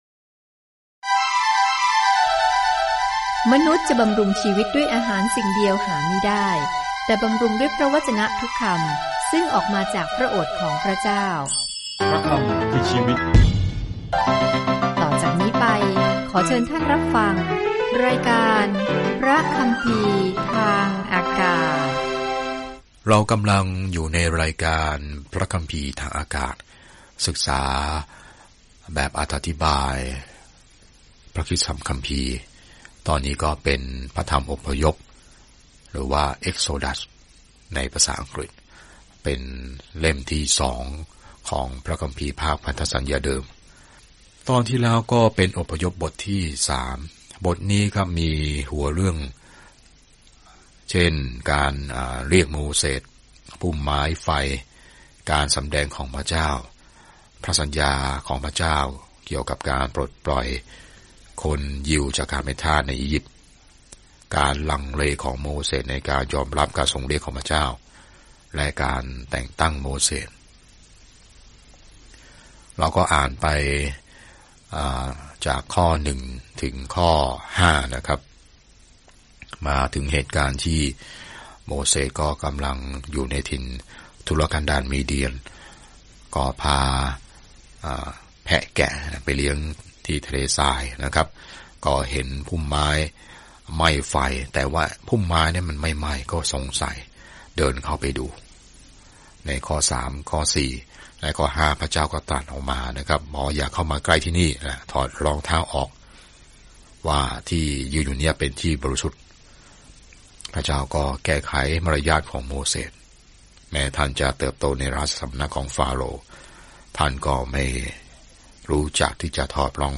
อพยพติดตามการหลบหนีของอิสราเอลจากการเป็นทาสในอียิปต์และบรรยายถึงทุกสิ่งที่เกิดขึ้นระหว่างทาง เดินทางทุกวันผ่าน Exodus ในขณะที่คุณฟังการศึกษาด้วยเสียงและอ่านข้อที่เลือกจากพระวจนะของพระเจ้า